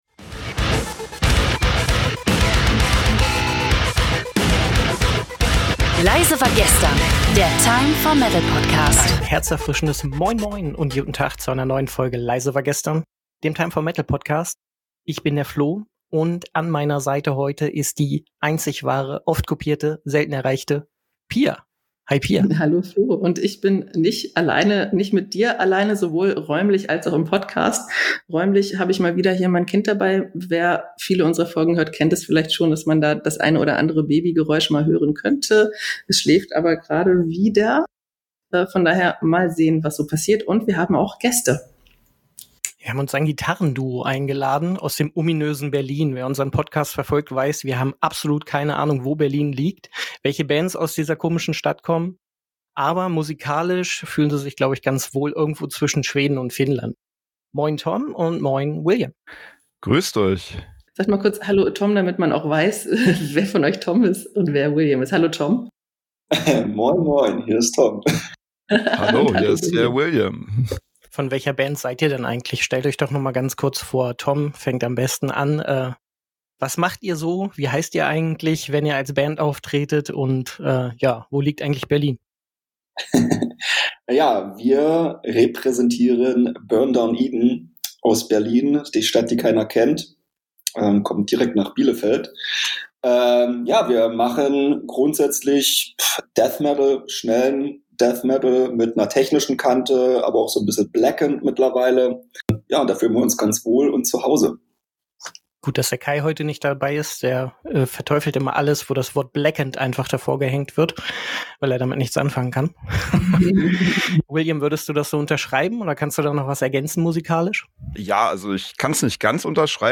Die dritte Staffel des "Leise War Gestern" Podcasts vom Online-Magazin Time For Metal startet mit einer explosiven Mischung aus exklusiven Interviews, tiefgründigen Diskussionen und großartiger Musik für Metal- und Rock-Fans.